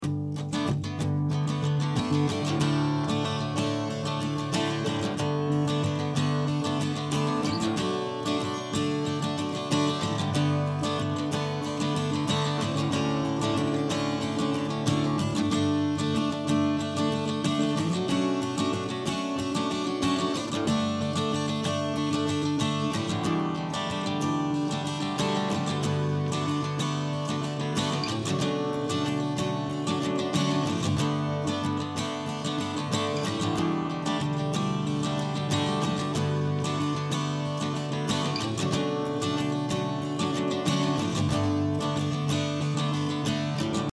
Das Ausgangsmaterial sieht so aus: 2 x akustische Gitarre.
Danach ein Delay was Breite gibt und eben das Spring Reverb.
Und R sieht das so aus: Die linke Seite Dark Ambience, die Rechte eher Clean.